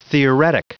Prononciation du mot theoretic en anglais (fichier audio)
Prononciation du mot : theoretic